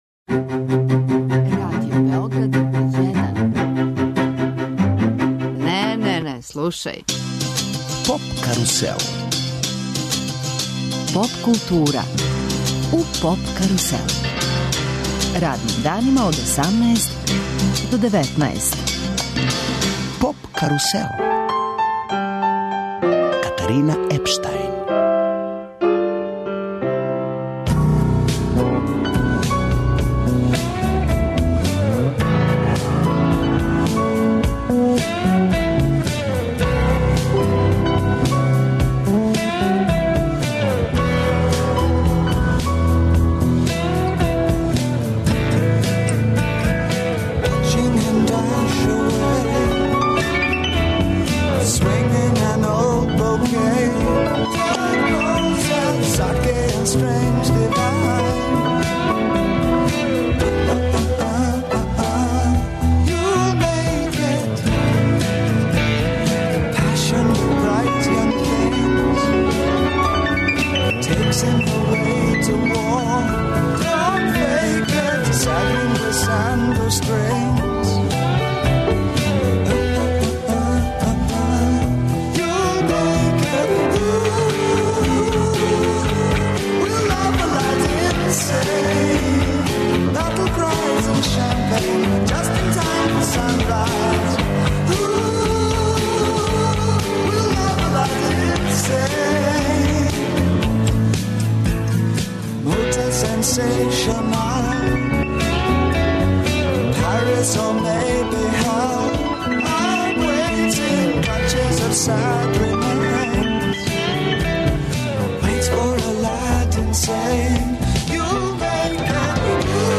Слушамо нове песме и разговарамо о предстојећим плановима за лето.